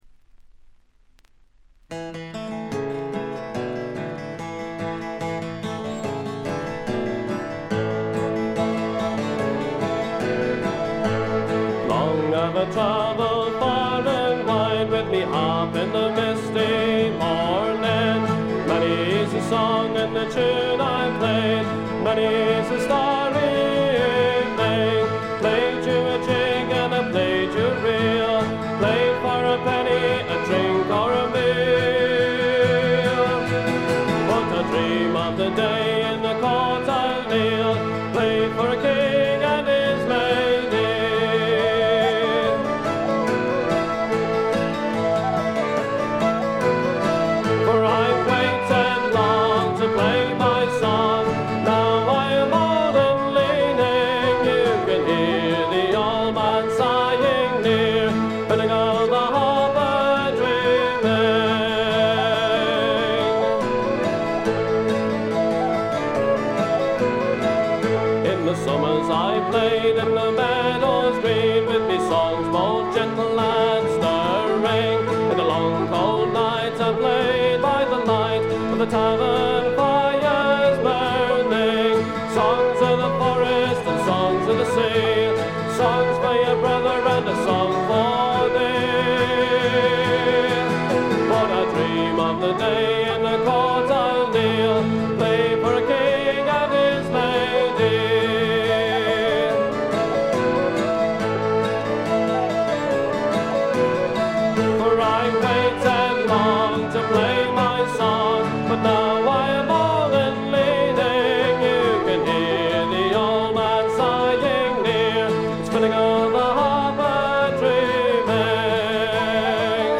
見た目に反してバックグラウンドノイズやチリプチがそこそこ出ます。
本作のもの悲しい笛の音や寒そうな感覚は、おなじみのアイリッシュ・トラッドのような感じです。
アコースティック楽器のみで、純度の高い美しい演奏を聴かせてくれる名作です。
試聴曲は現品からの取り込み音源です。